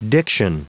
Prononciation du mot diction en anglais (fichier audio)
Prononciation du mot : diction